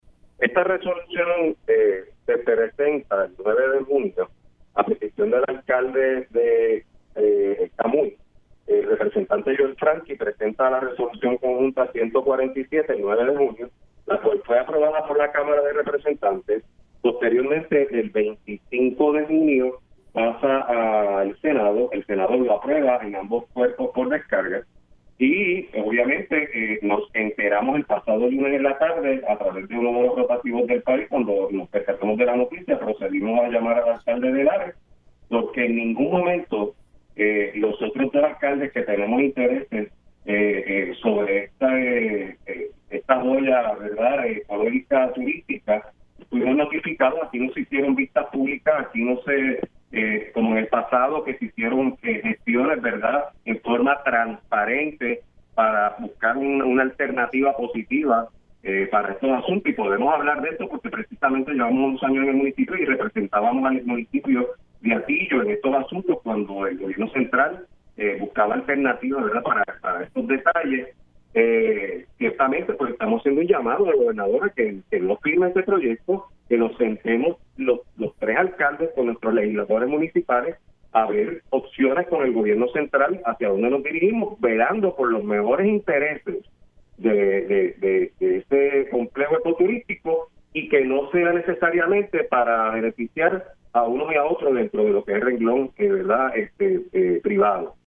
El alcalde de Hatillo, Carlos Román criticó en Pega’os en la Mañana la aprobación de la Resolución Conjunta de la Cámara 147, una medida legislativa transferirá al Municipio de Camuy el Parque Nacional de las Cavernas del Río Camuy.